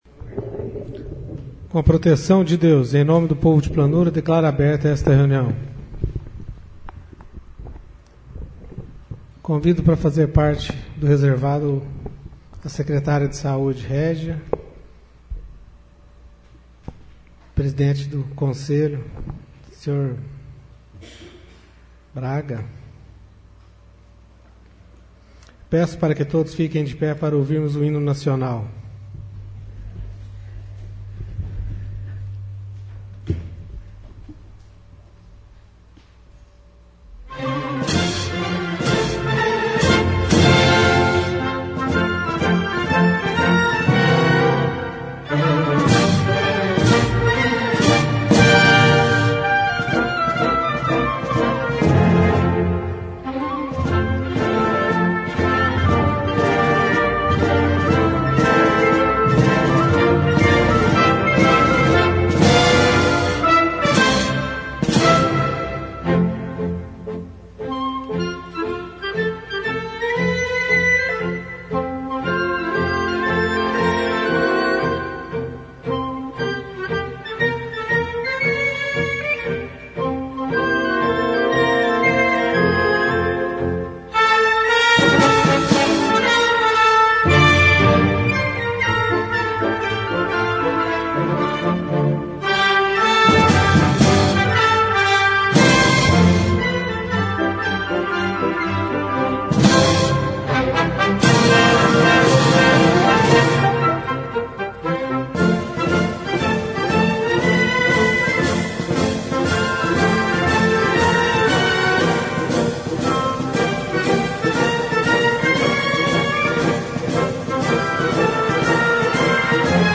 Sessão Ordinária - 05/05/14